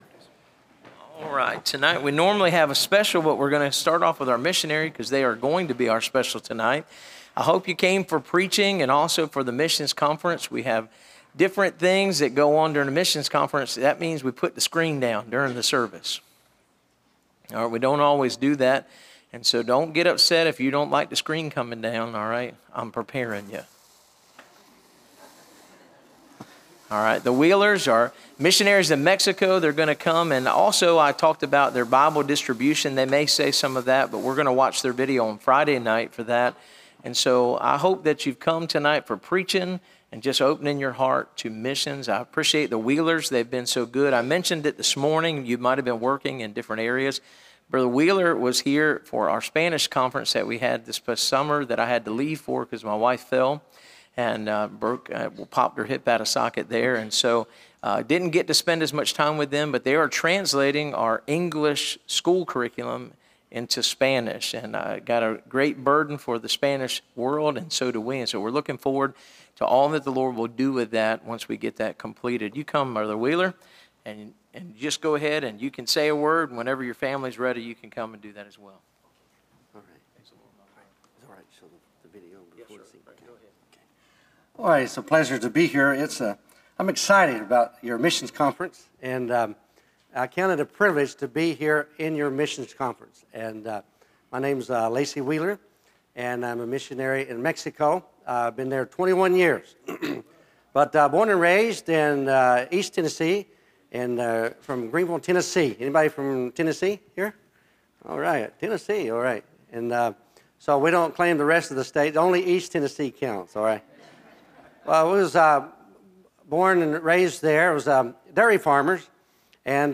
Series: (Missions Conference 2025)